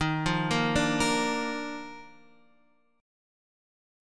D#M7sus2 chord